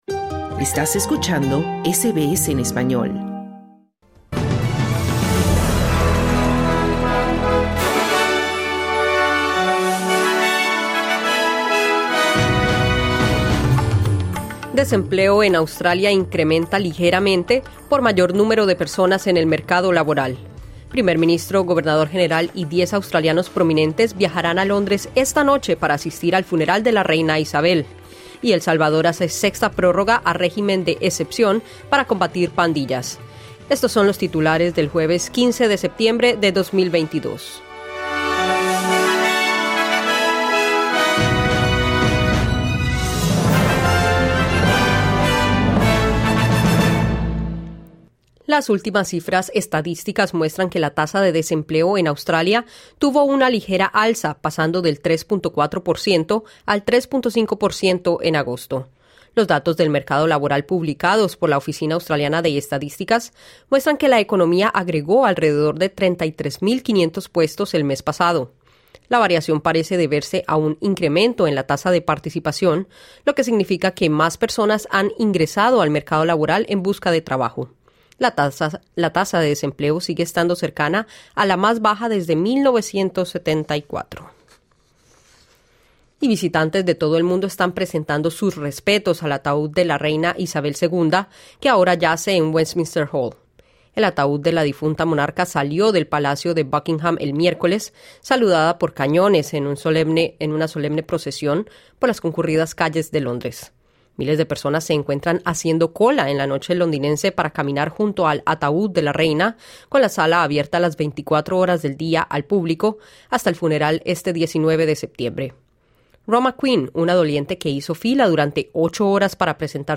Noticias SBS Spanish | 15 septiembre 2022